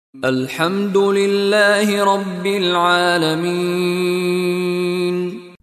Contoh Bacaan dari Sheikh Mishary Rashid Al-Afasy
DIPANJANGKAN sebutan huruf Mad dengan kadar 2, 4 – 6 Harakat (ketika berhenti)